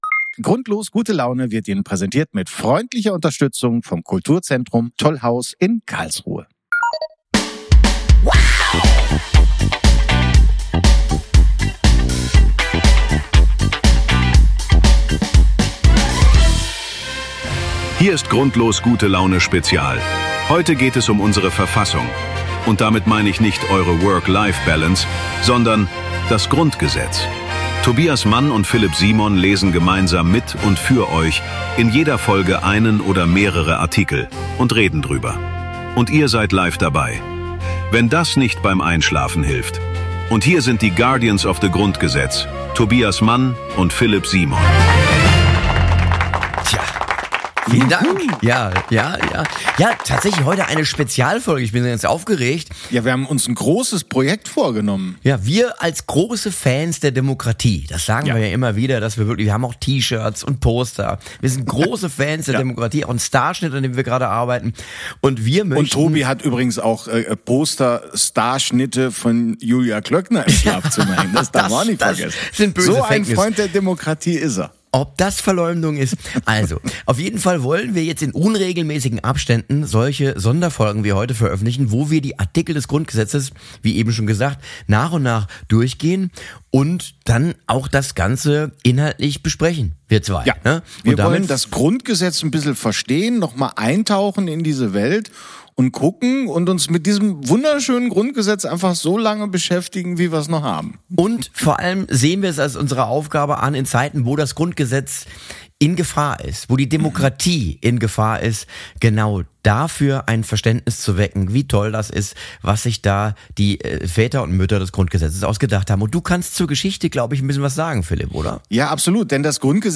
Tobias Mann und Philip Simon lesen das komplette Grundgesetz - also nicht auf einmal, aber immer mal wieder!